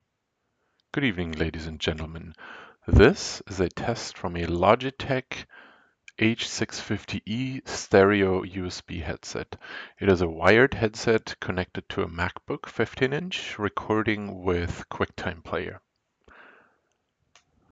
Logitech H650eUSB wired headset (mono)
logitech-h650e.m4a